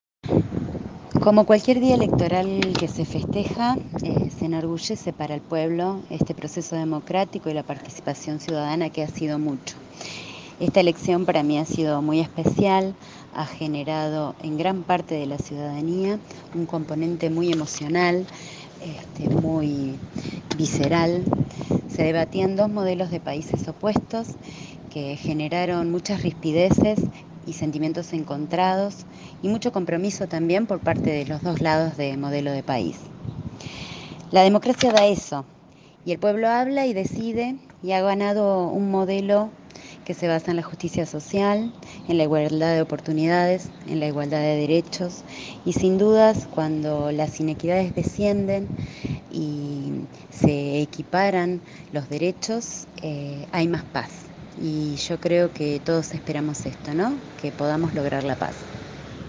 Entrevista a Brenda Vimo, concejala electa de Rafaela.